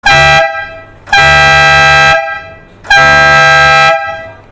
Horn (12 V), completely with air compressor + 2 x compressed air horn, relay and air hoses. As substitute for the original horns of Citroen DS. 750 + 800Hz (2 different tones). 115dB (A).